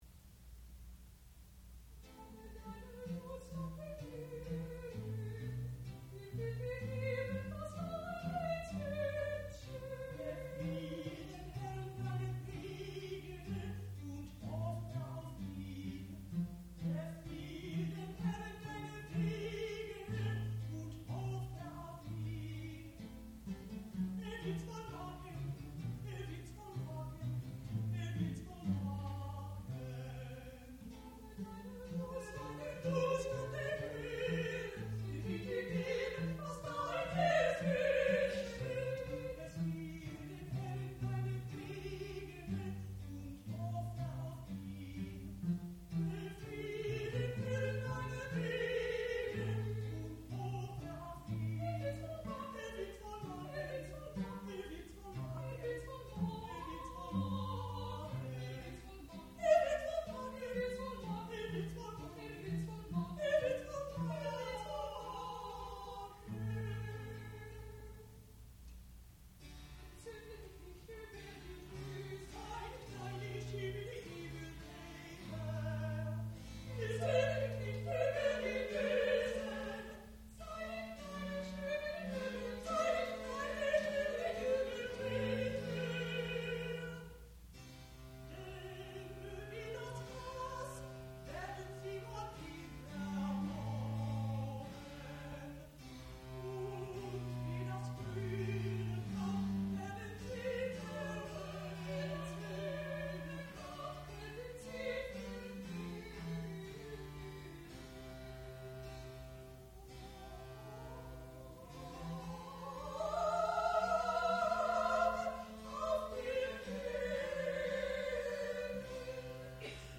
sound recording-musical
classical music
mezzo-soprano
piano
viola da gamba
Graduate Recital